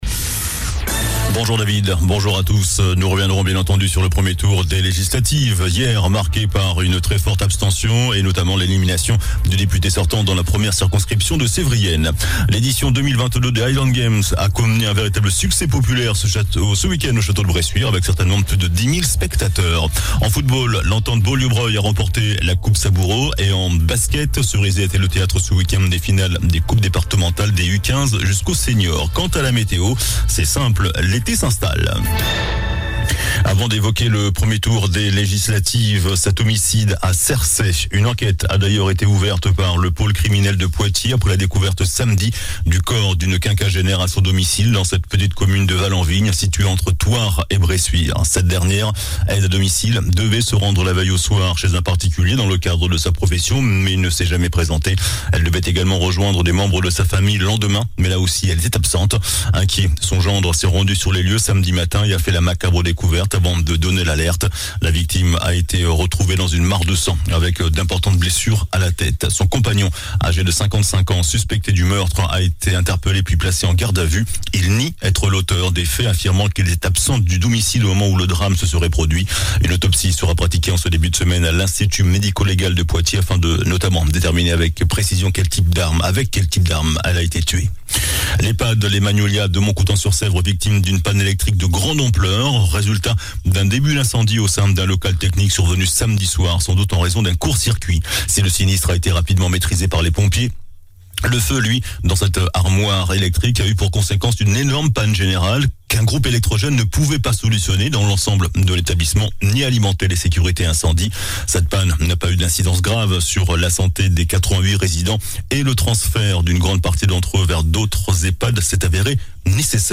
JOURNAL DU LUNDI 13 JUIN ( MIDI )